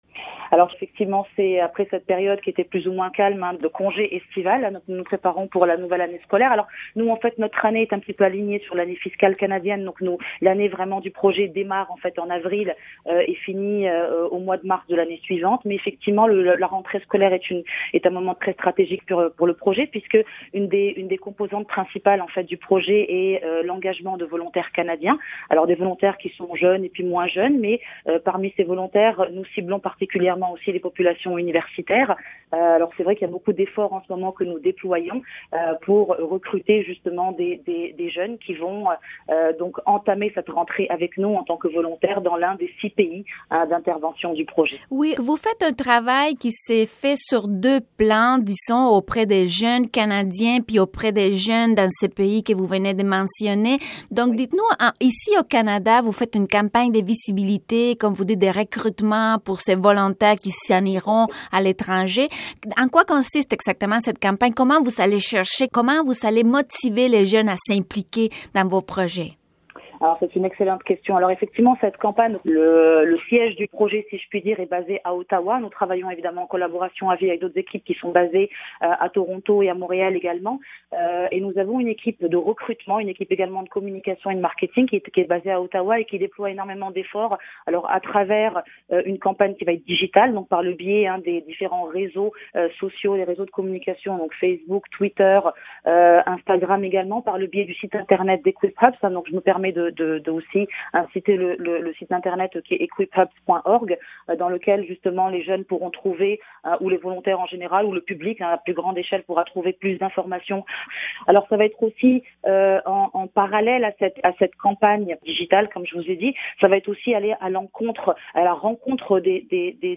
L'interviewée